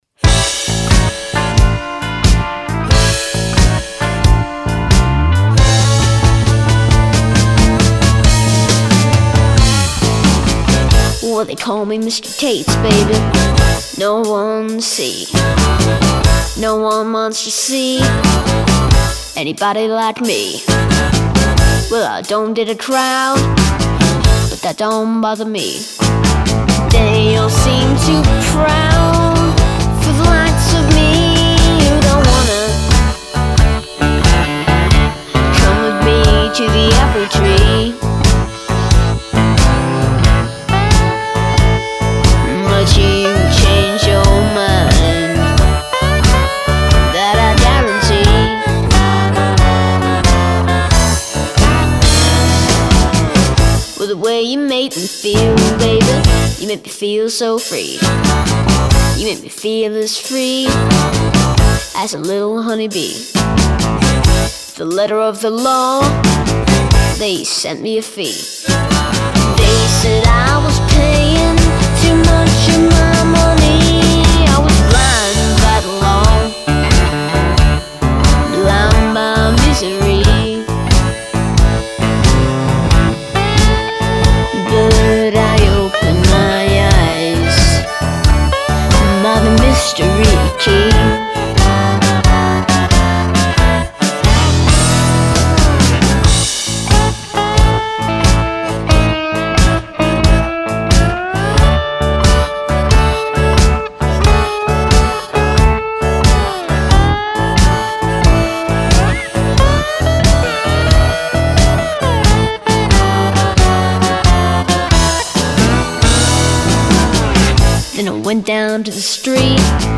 I Like to perform a cool solo in each of my songs
A modern twist for a blues song.